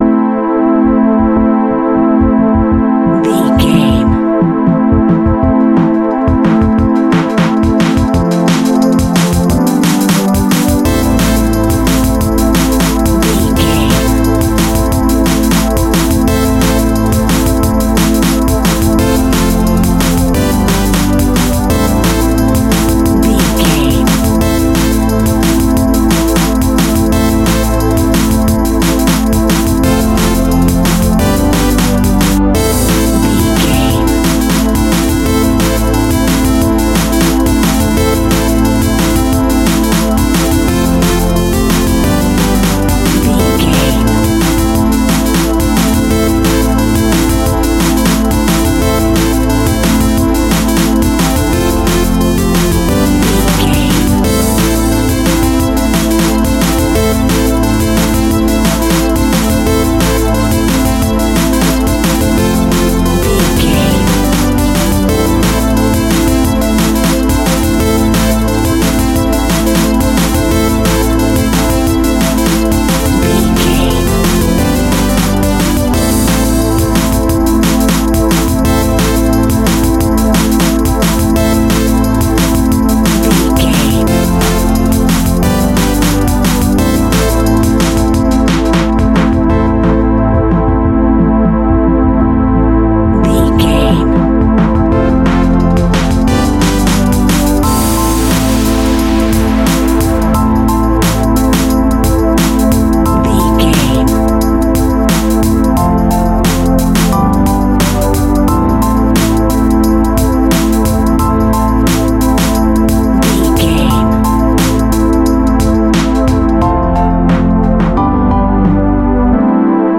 Drum and Bass In the Future.
Aeolian/Minor
Fast
aggressive
powerful
dark
funky
groovy
futuristic
energetic
drum machine
synthesiser
electronic
sub bass
synth leads